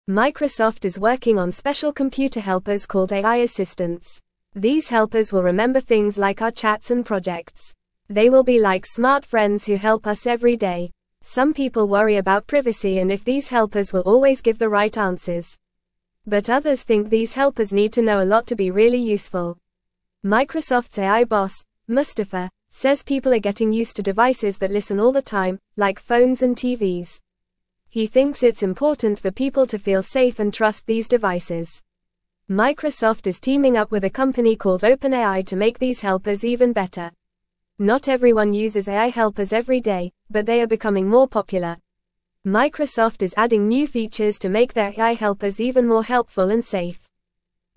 Fast
English-Newsroom-Beginner-FAST-Reading-Microsoft-is-Making-Smart-Friends-to-Help-You.mp3